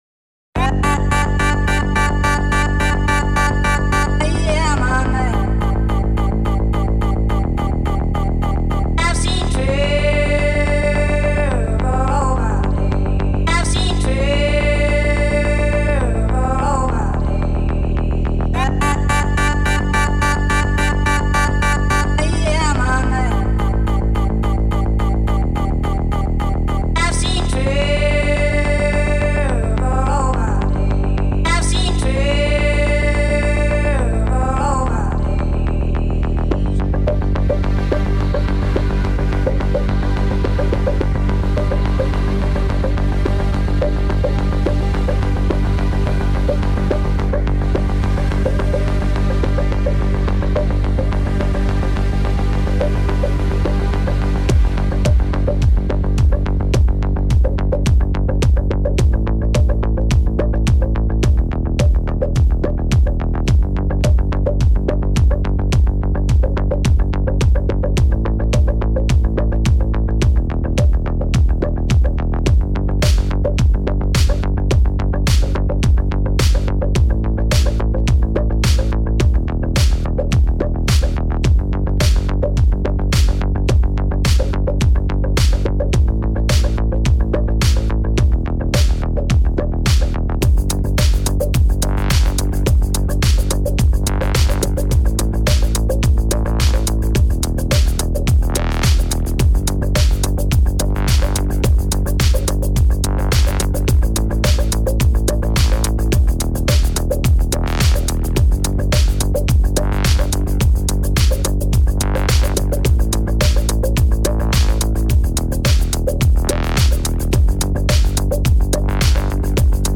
Expect a super eclectic mix of party bangers.